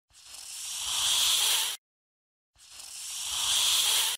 Snake (Hiss) Sound Effect Pro Sounds Rx Jo I Yz3yqk (audio/mpeg)
SNAKE